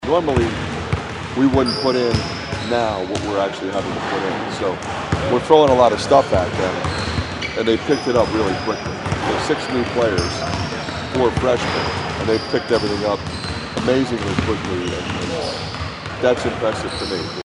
That’s Iowa coach Fran McCaffery. The Hawkeyes have six new players, including four freshmen, and McCaffery has been impressed with how quickly they have picked things up.